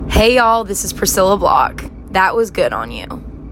LINER Priscilla Block (Good On You) 6